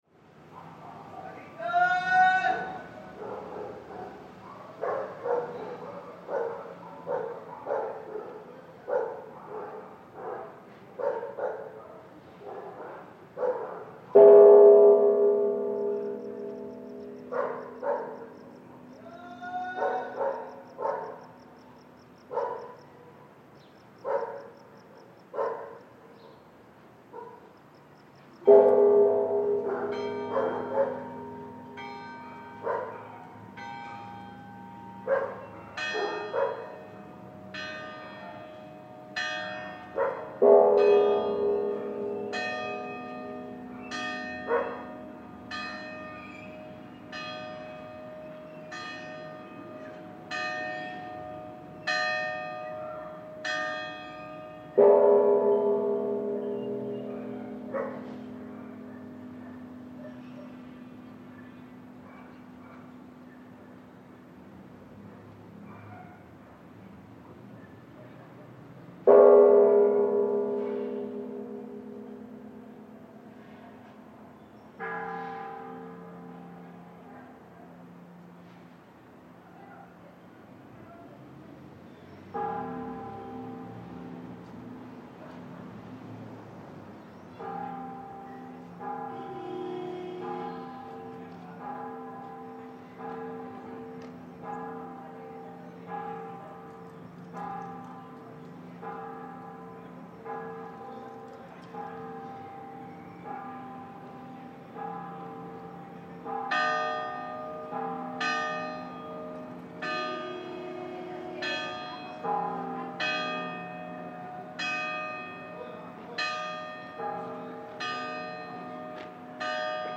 Sounds from San Pascual alley at Zacatecas downtown. Stereo 48kHz 24bit.